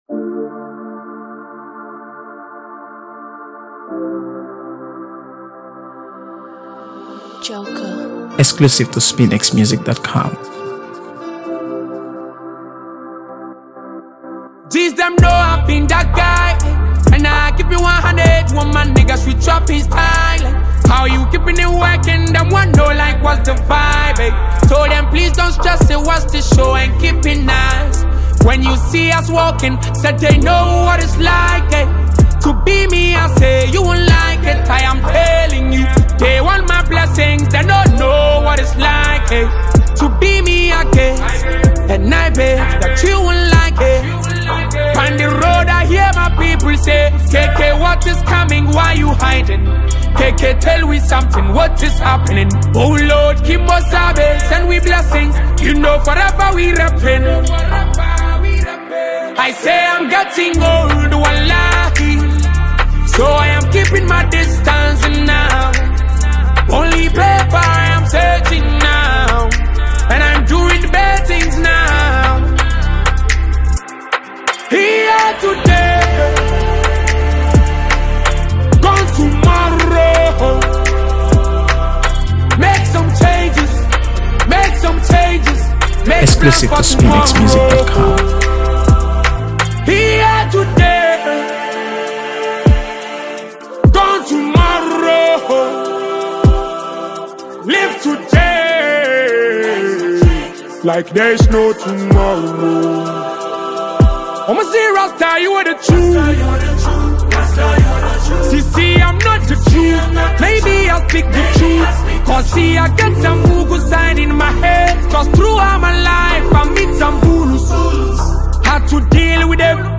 AfroBeats | AfroBeats songs
Ghanaian singer-songwriter and performer